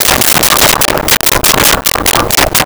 Chickens In A Barn 04
Chickens in a Barn 04.wav